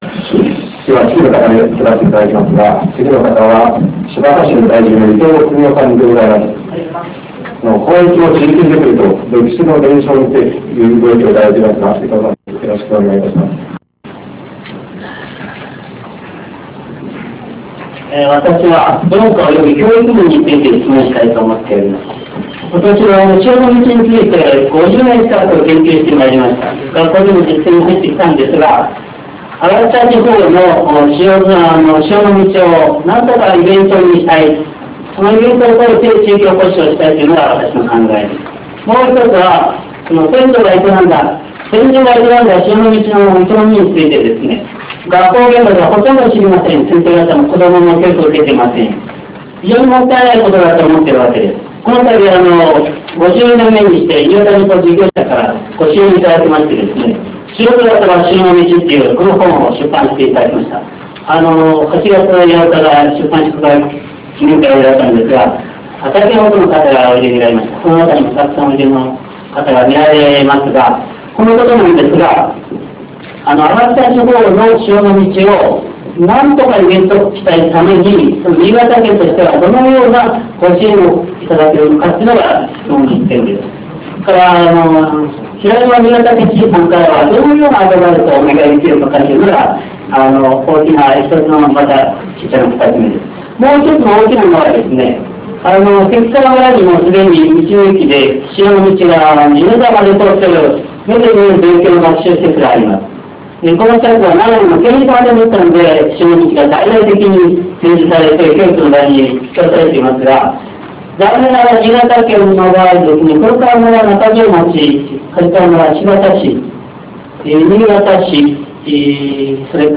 各種講演会の様子
「知事とふるさとを考える集い」（中条町）